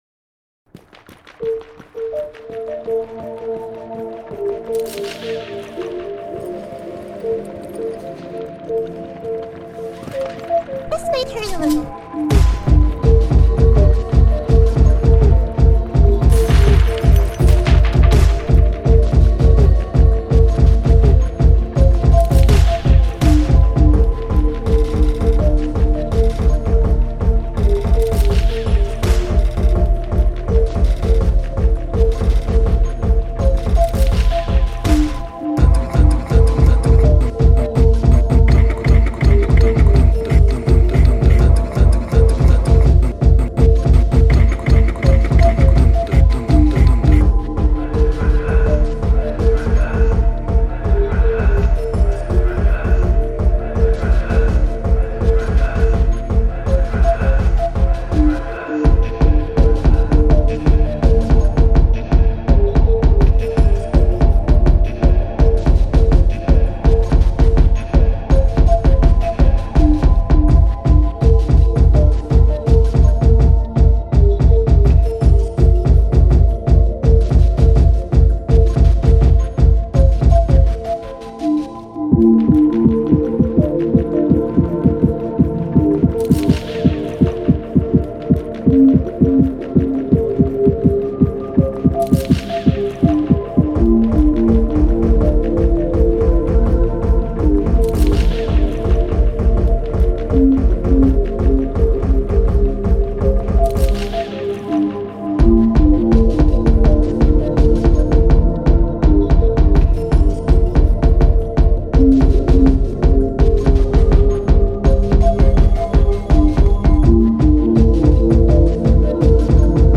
It's a track made with sounds and samples from games that friends were streaming in discord while hangin out. It's got some Rainbow six, some No man's sky, some vampire survivor, and some Katamari in it.
I think a more ambient approach to jersey club is somethin worth exploring further in the future, but for now this is all i got.